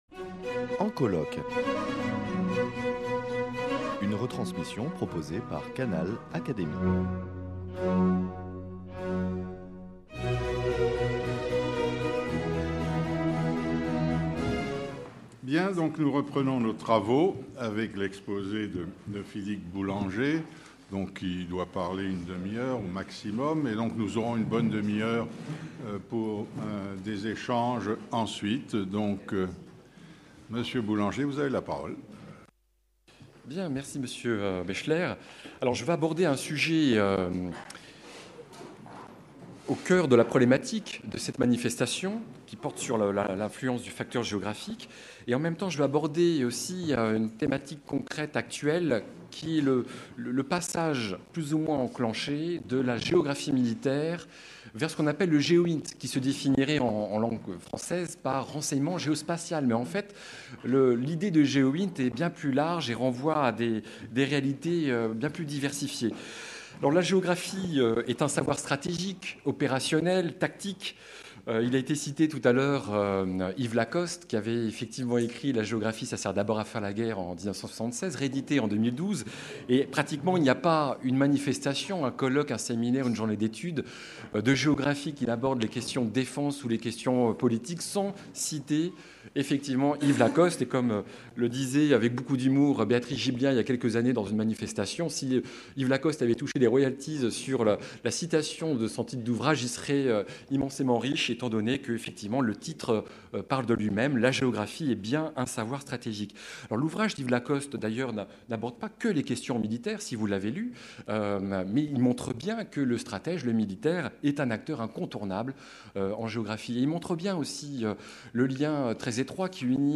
Retransmission des journées d’études « Guerre et géographie » - Partie 2